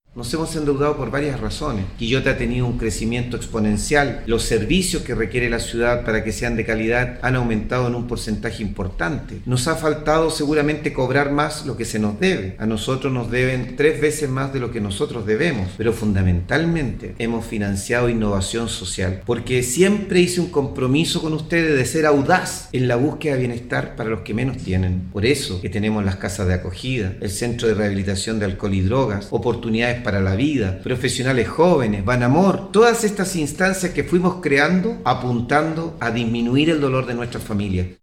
03-ALCALDE-MELLA-Razones-del-endeudamiento.mp3